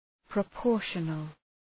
Shkrimi fonetik {prə’pɔ:rʃənəl}